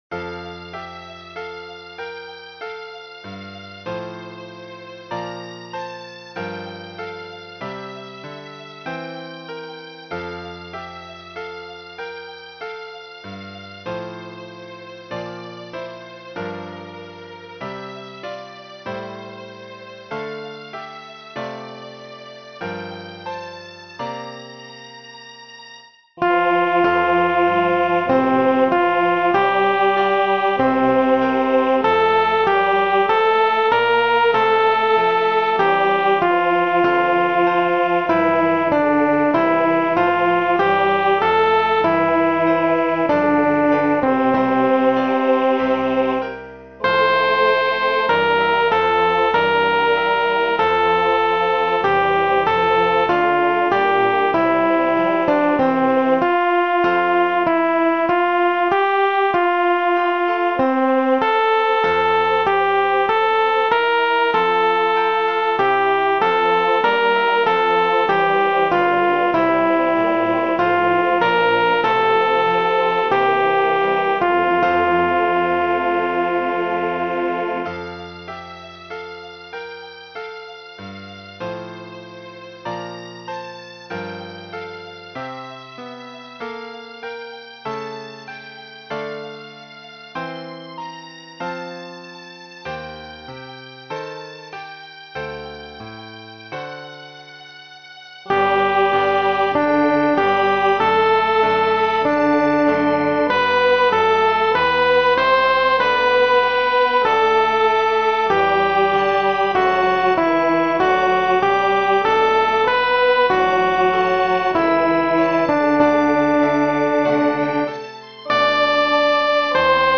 ソプラノ（フレットレスバス音）